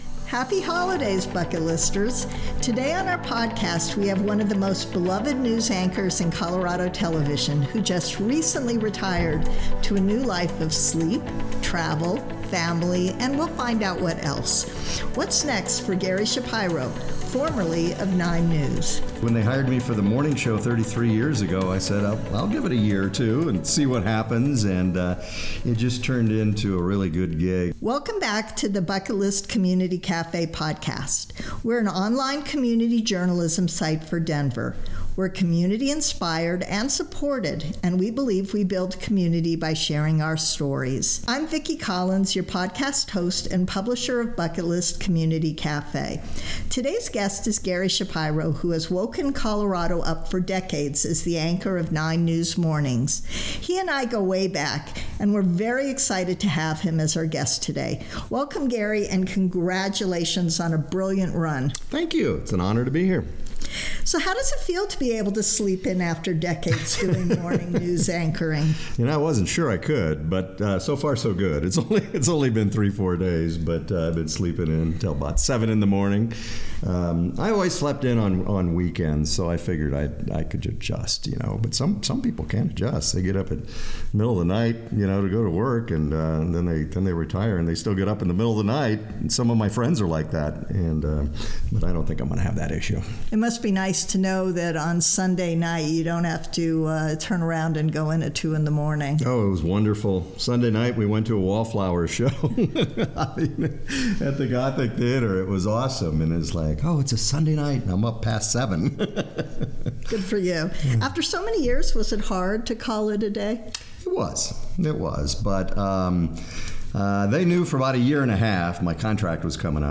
He sat down with Bucket List Community Cafe's podcast.